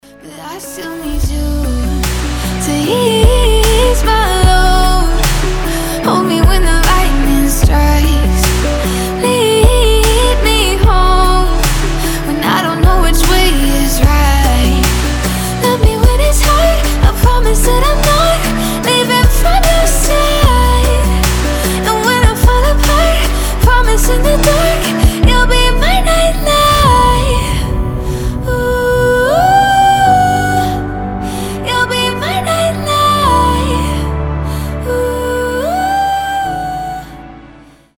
женский голос спокойные
Романтичные